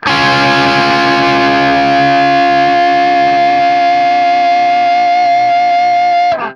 TRIAD C#  -L.wav